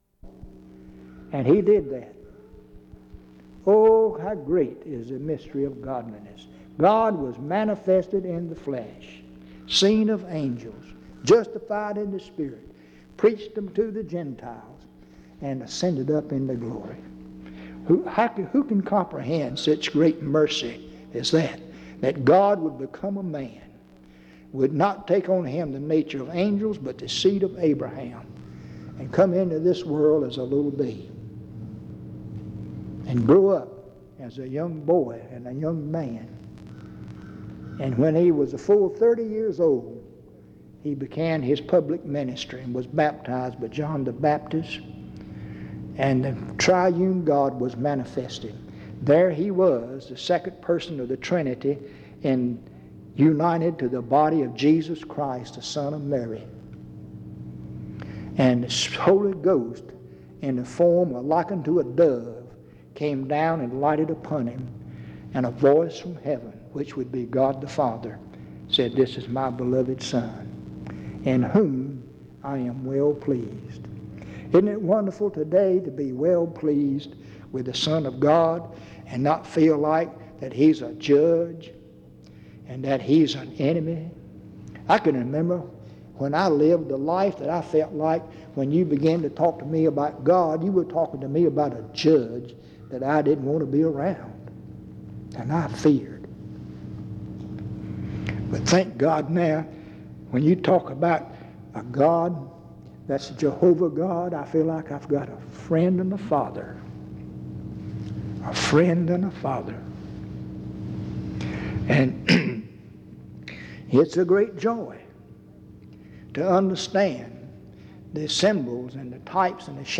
En Collection: Reidsville/Lindsey Street Primitive Baptist Church audio recordings Miniatura Título Fecha de subida Visibilidad Acciones PBHLA-ACC.001_010-A-01.wav 2026-02-12 Descargar PBHLA-ACC.001_010-B-01.wav 2026-02-12 Descargar